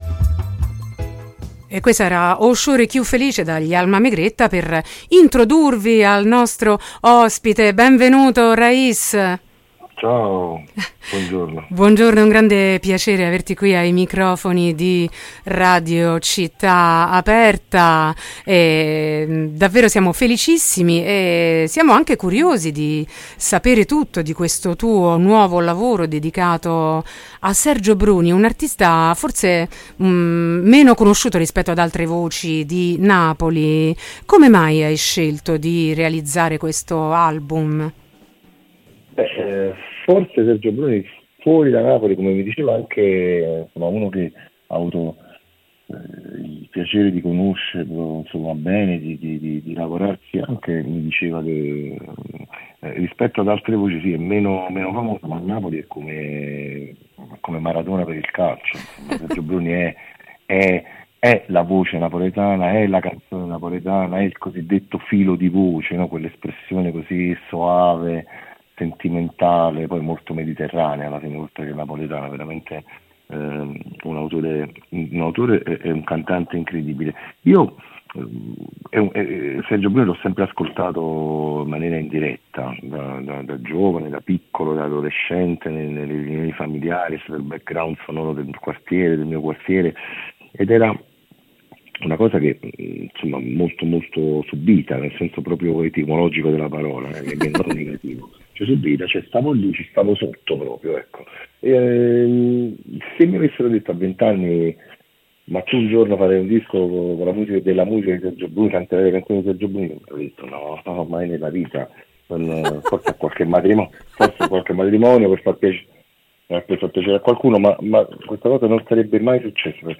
La ricchezza di un’identità molteplice: intervista a Raiz | Radio Città Aperta
intervista-raiz.mp3